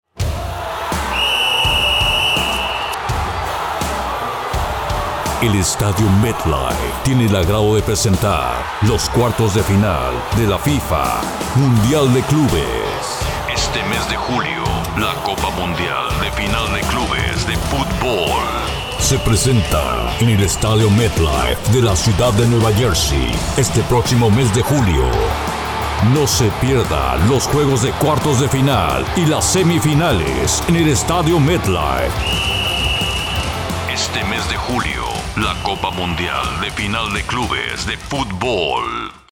Male
Voice over Talent with Deep , nice and friendly , Spanish accent for english spots and translations.
Words that describe my voice are Deep, Comercial, promo.
All our voice actors have professional broadcast quality recording studios.
0603Holiday___Special_events.mp3